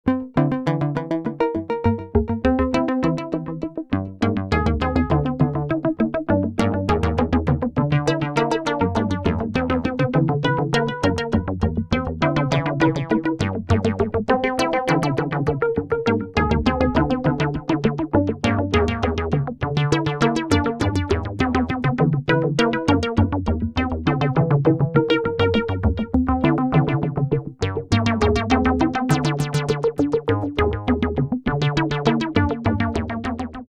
The next two links/descriptions are UEG specific demos created by Robert Rich.
Faster version of above with more dynamic filter tweaks.